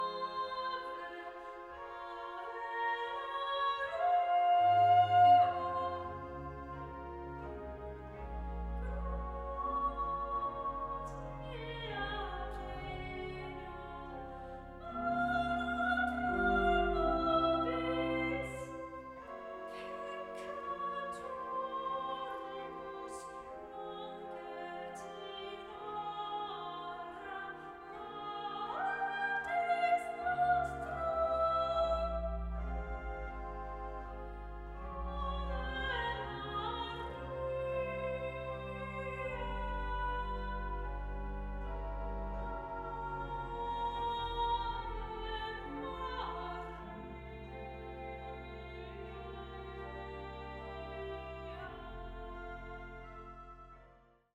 Klassische Kirchenkonzerte